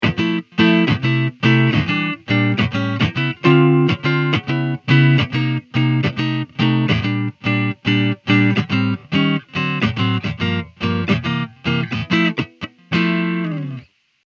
Walking Jazz with lead